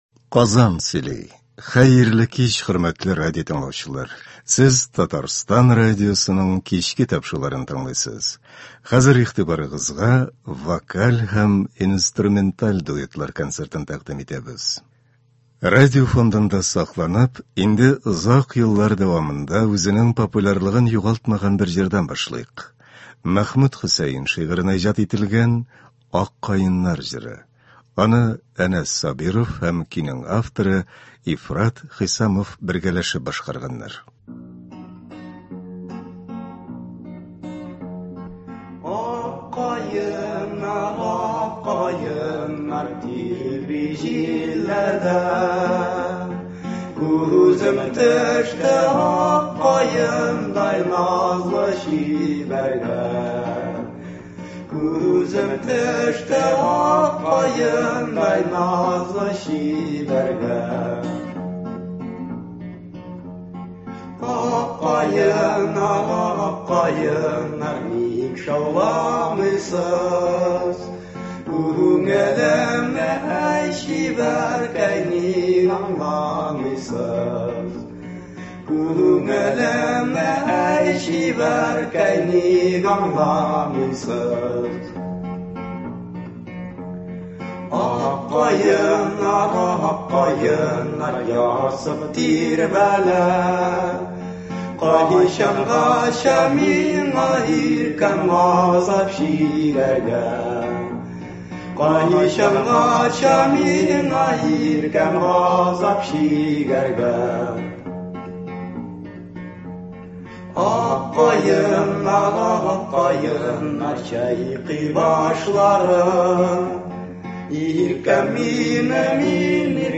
Вокаль һәм инструменталь дуэтлар.